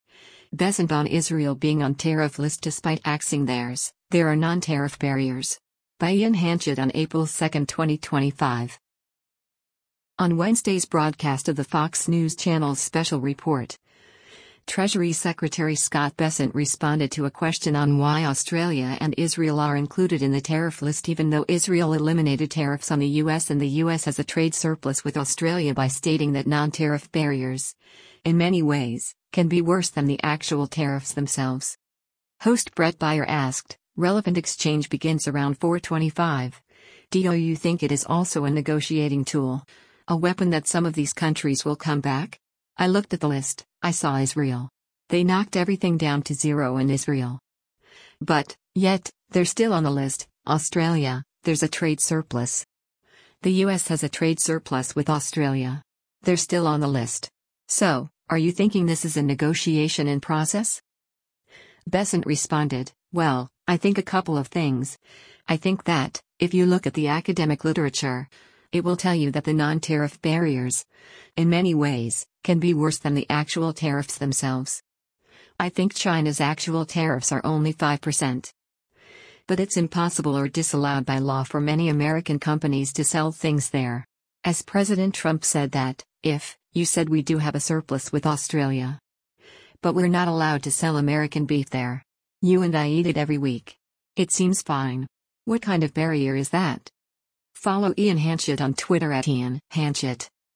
On Wednesday’s broadcast of the Fox News Channel’s “Special Report,” Treasury Secretary Scott Bessent responded to a question on why Australia and Israel are included in the tariff list even though Israel eliminated tariffs on the U.S. and the U.S. has a trade surplus with Australia by stating that “non-tariff barriers, in many ways, can be worse than the actual tariffs themselves.”